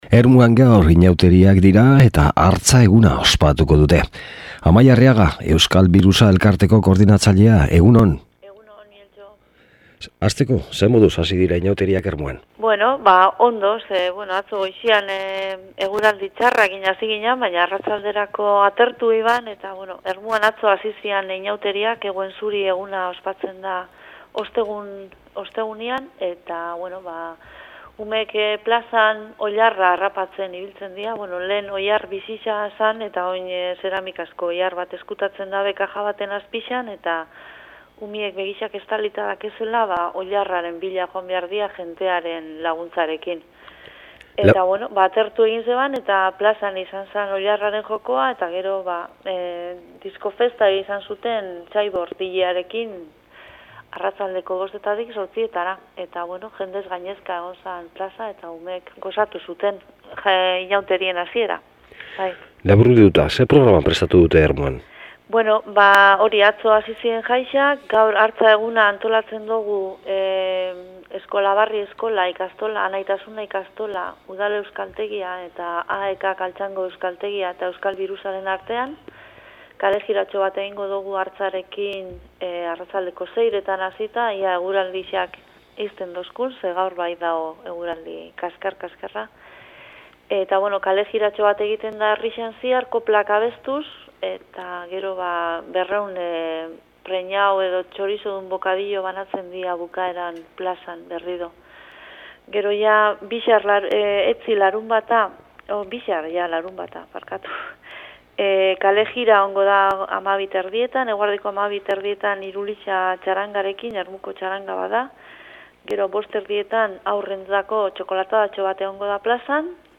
solasaldia